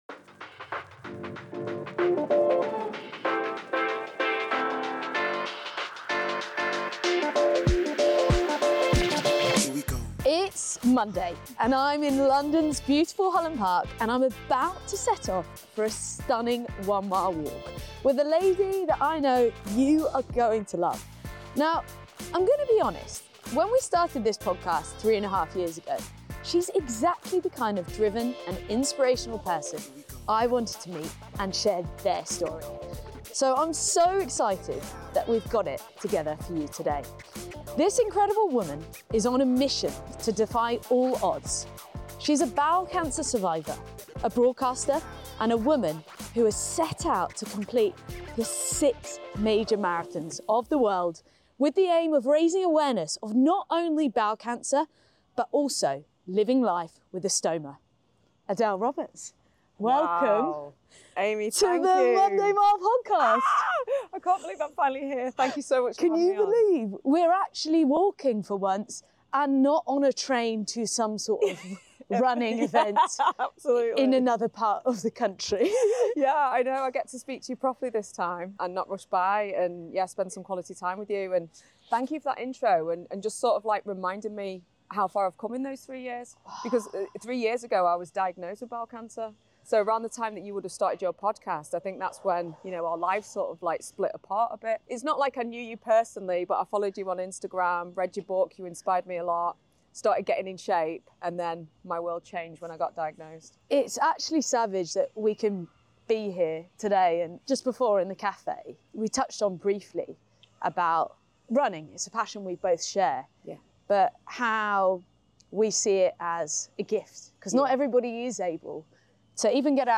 Welcome back to a brand new series of the Monday Mile podcast! This week, Aimee is joined by the incredible Adele Roberts.